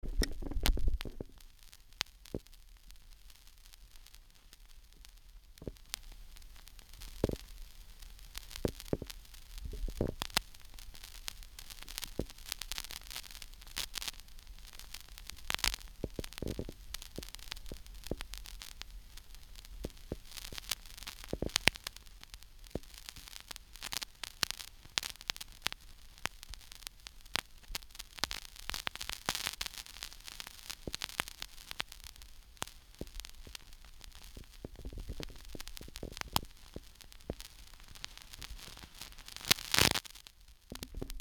My Fostex 250 cassette recorder makes crackling noise (recording inside)
The crackling sound is always there when the machine is on, also when there is no tape and when it's not recording or playing anything.
Attachments tapenoise.MP3 tapenoise.MP3 1.6 MB · Views: 38